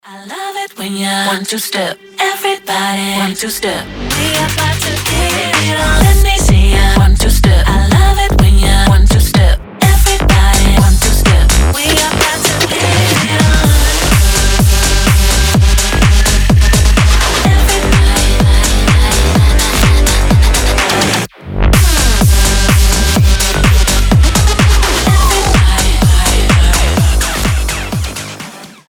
• Качество: 320, Stereo
ритмичные
женский вокал
Tech House
залипающие
Славный tech house